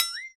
otherShortJump.wav